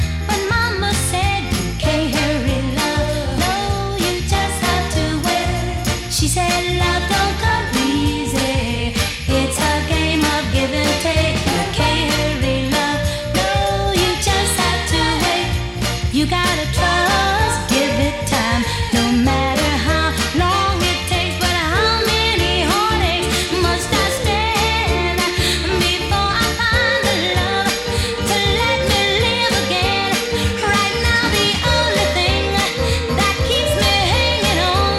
Жанр: R&B / Соул